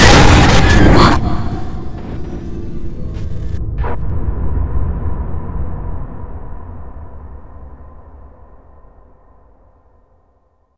Boom.wav